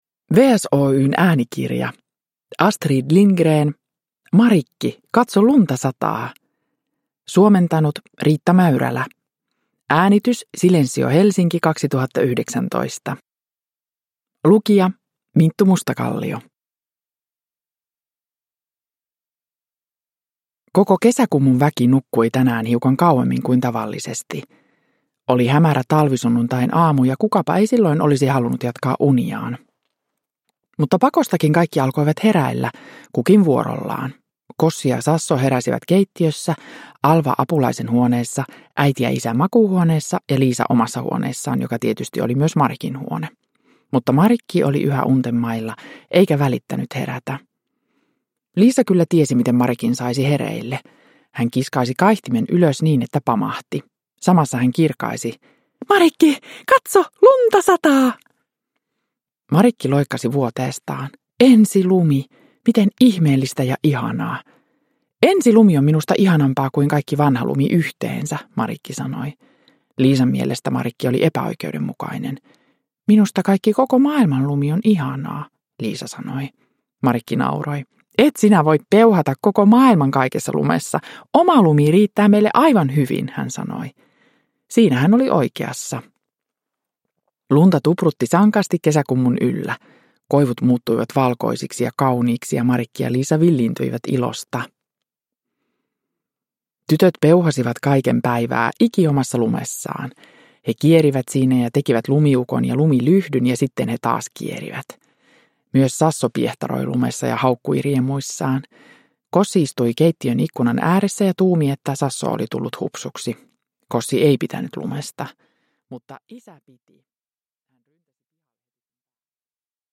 Marikki, katso, lunta sataa! – Ljudbok – Laddas ner
Uppläsare: Minttu Mustakallio